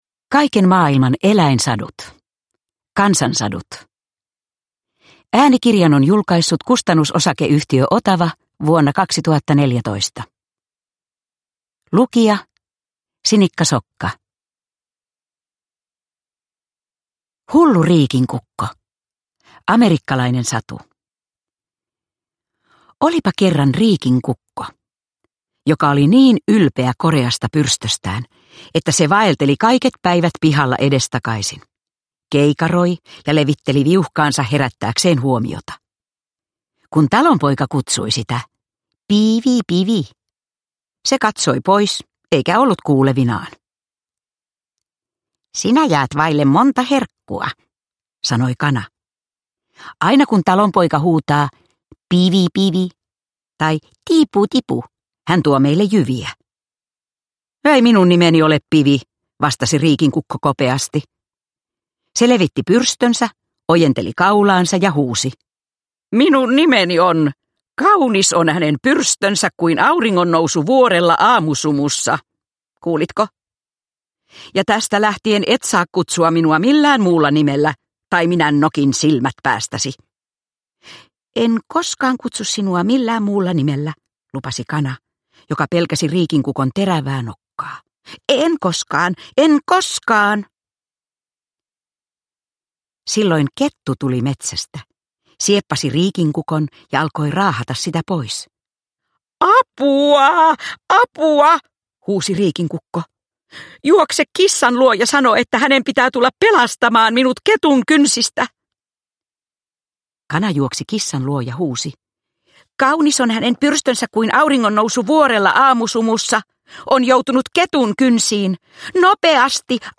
Kaiken maailman eläinsadut – Kansansadut – Ljudbok – Laddas ner